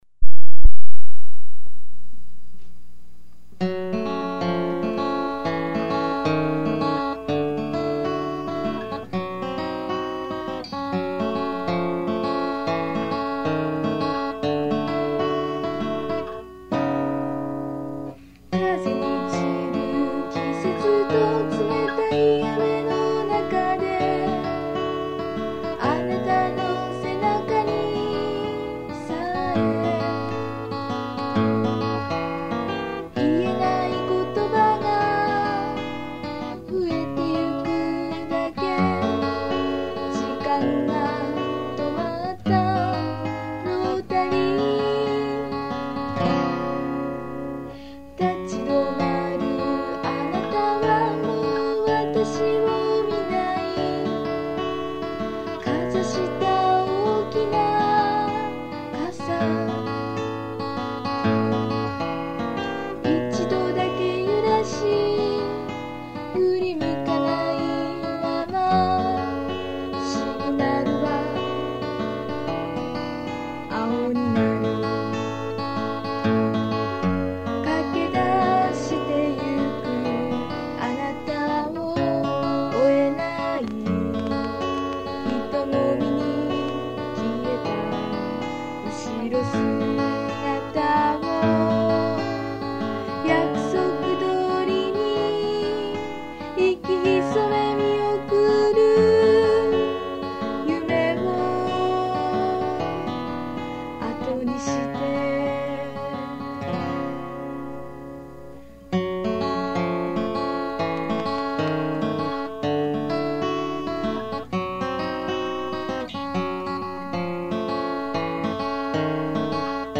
（Original　Key : F♯minor, by acoustic guitar）